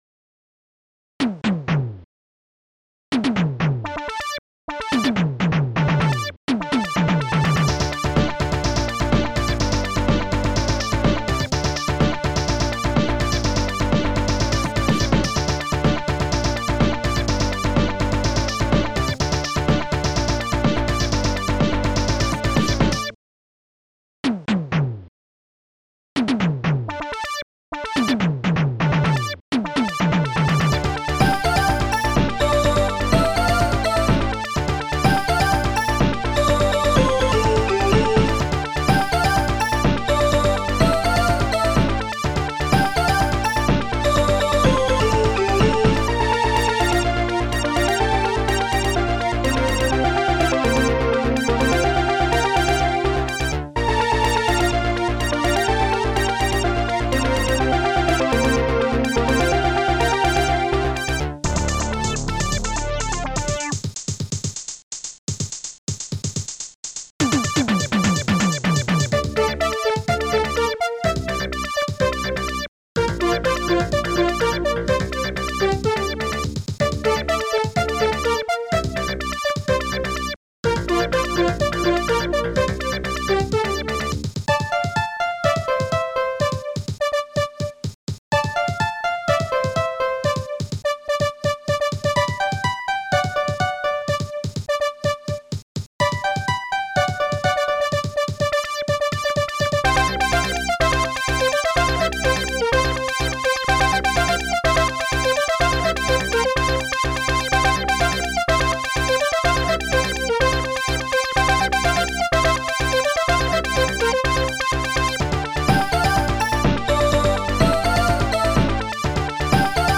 st-01:bassdrum2
st-01:strings1
st-01:hallbrass
st-01:steinway
st-01:funkbass